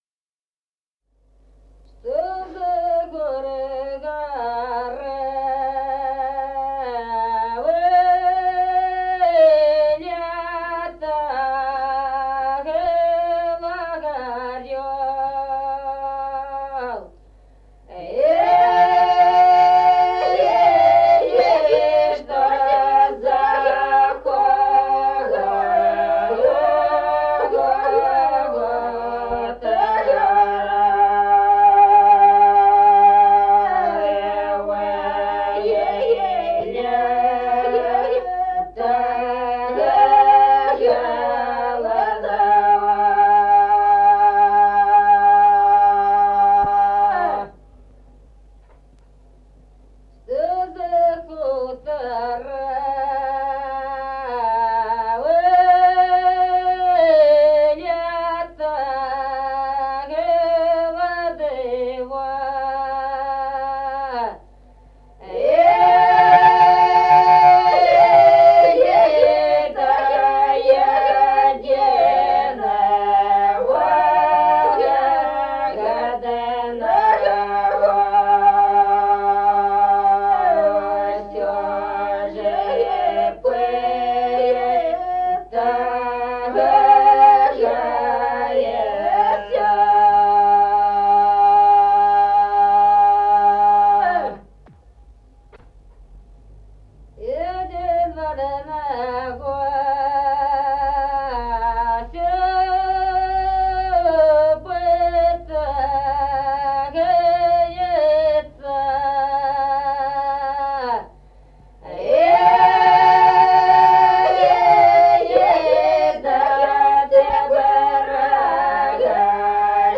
Народные песни Стародубского района «Что из-за гор, горы», воинская.
(запев)
(подголосник)
1953 г., с. Остроглядово.